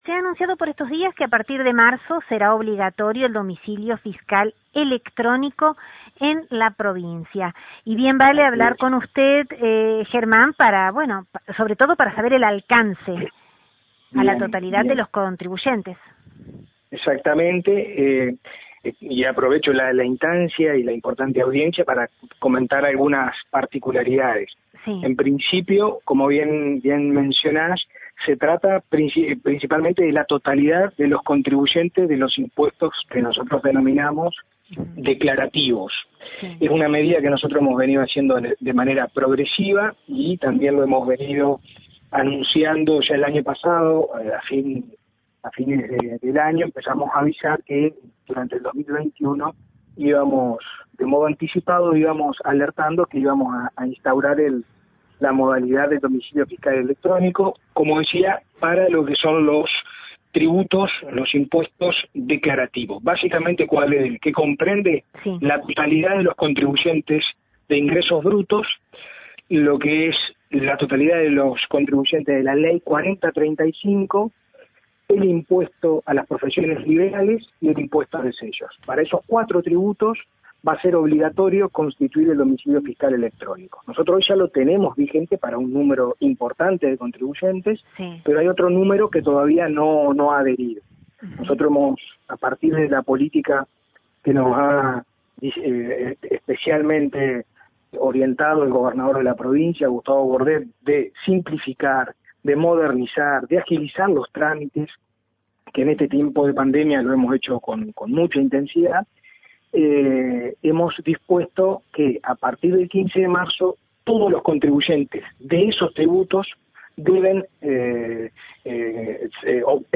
El titular de ATER, Germán Grané, en diálogo con LT39 NOTICIAS, destacó que “la medida va en sintonía con la línea de trabajo establecida por el gobernador Gustavo Bordet de hacer más eficiente los servicios y en este caso con la agilidad y dinámicas de las comunicaciones con los contribuyentes vía internet y portando validéz y eficacia jurídica”; agregando a posteriori, que el objetivo es llegar a un número más amplio de contribuyentes cuyas notificaciones y gestiones de diversos trámites, sean equiparables legalmente con el domicilio fiscal tradicional.